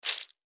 squish.mp3